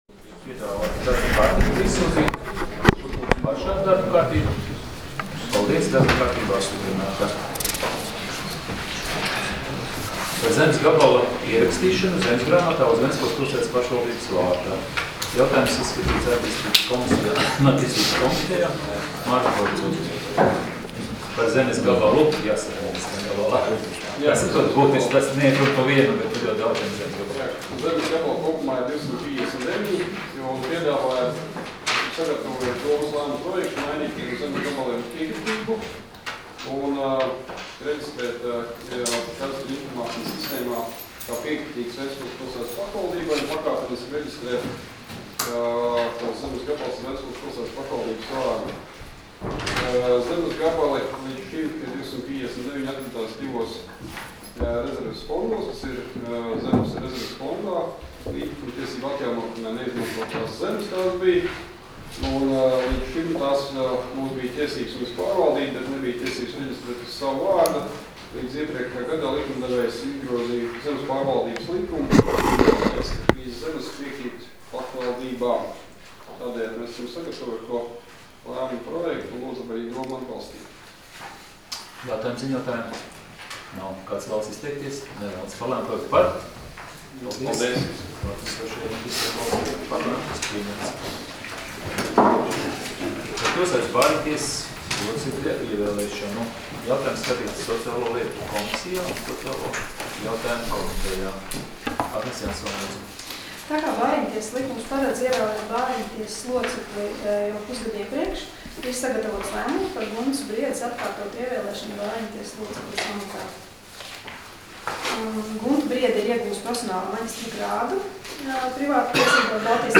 Domes sēdes 21.02.2019. audioieraksts